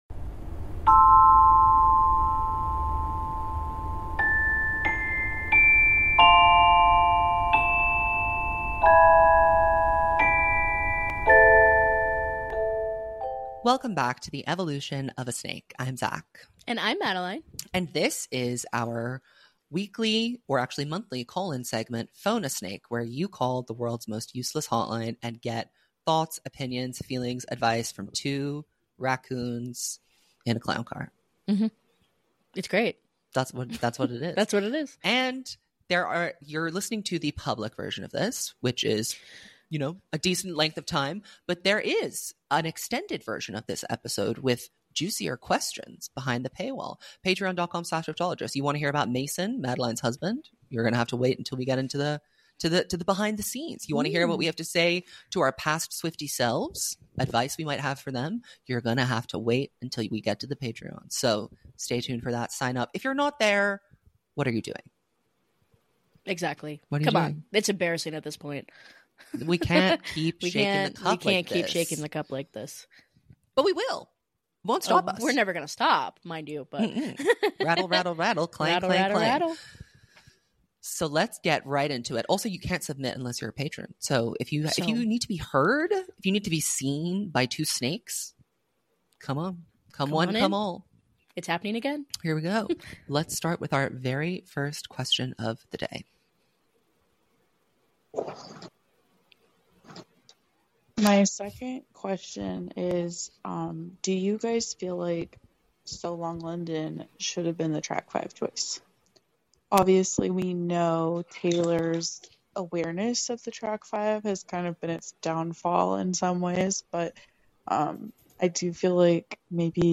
In this episode, where we take your urgent calls, we explore the significance of track five songs on Taylor's albums and how they evoke strong emotions. Also on the menu: the criticism Taylor receives and her thoughtful responses, touching on the bloat in her recent albums and the need for more concise editing. We also examine the vulnerability in Taylor's songs and the delicate balance between being open and oversharing.